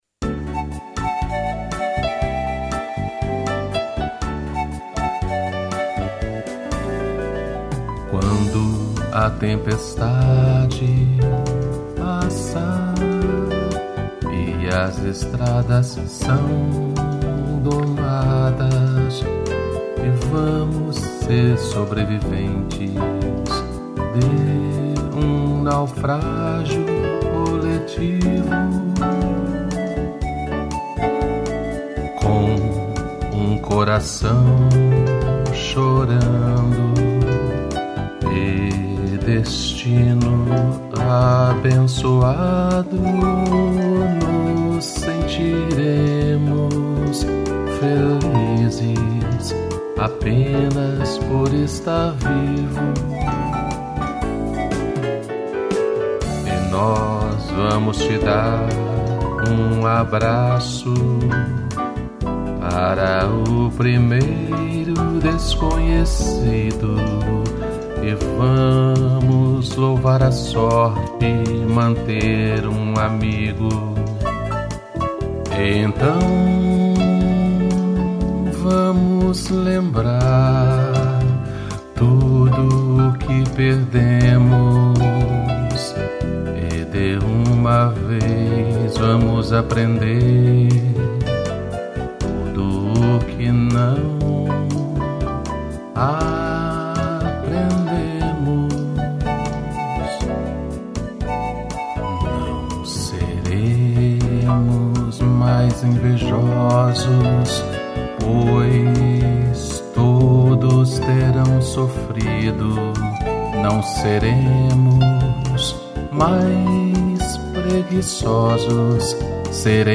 piano e flauta pan